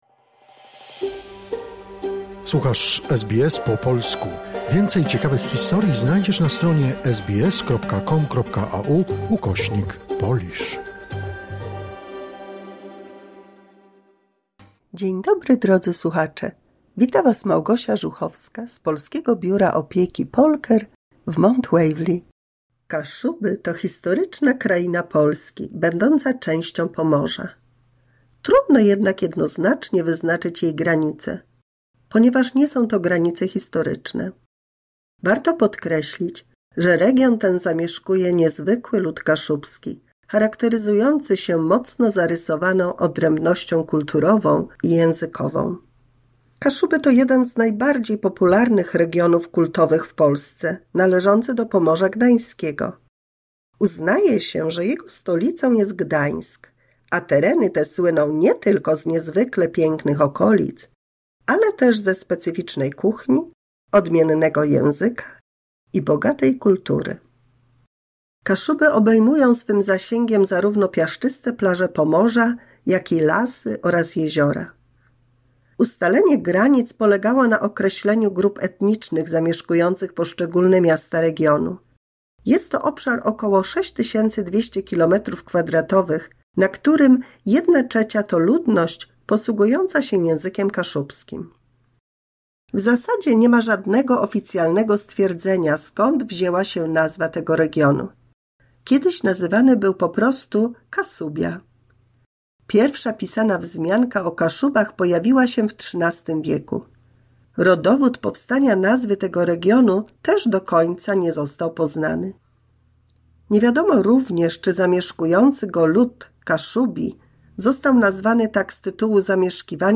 179 mini słuchowisko dla polskich seniorów